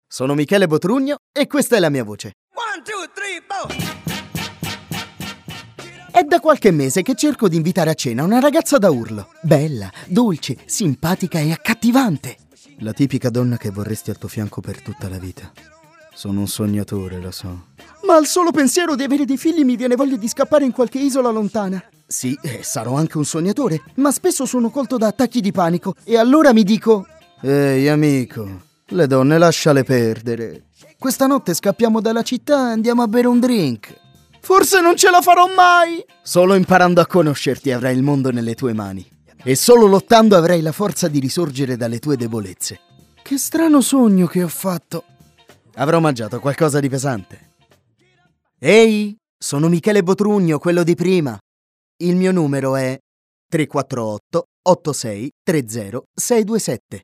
Speaker Italiano- doppiatore italiano- documentario italiano- voice over-radio-film
Kein Dialekt
Sprechprobe: eLearning (Muttersprache):